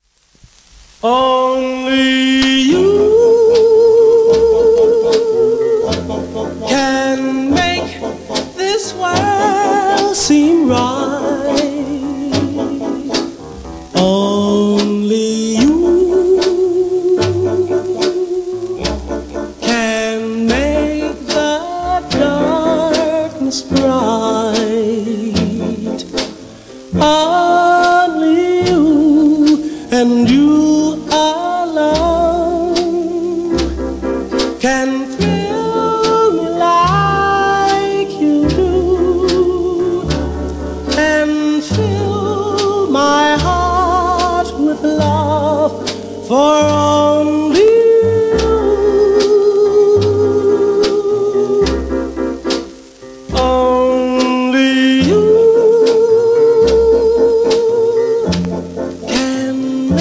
SP盤は大変珍しい